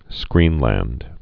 (skrēnlănd)